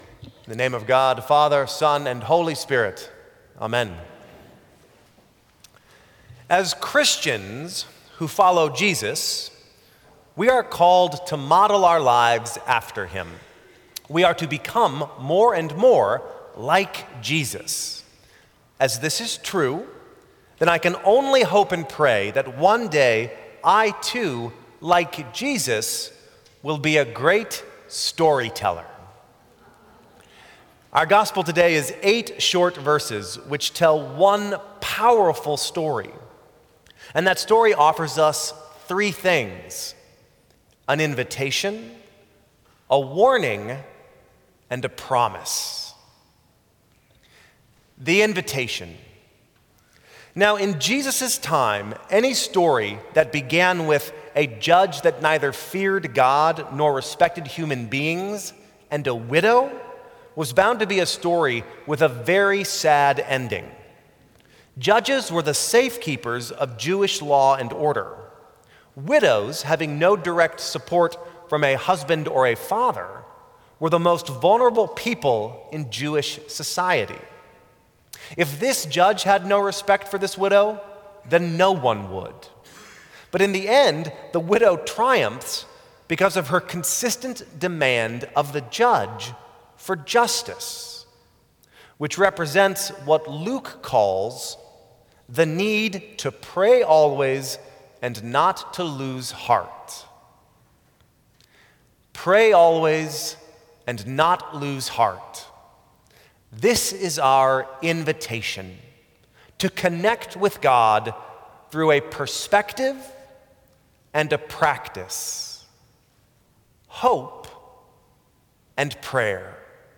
Sermons from St. Cross Episcopal Church 10/20/2013 Dec 17 2013 | 00:12:21 1x 00:00 / 00:12:21 Subscribe Share Apple Podcasts Spotify Overcast RSS Feed Share Link Embed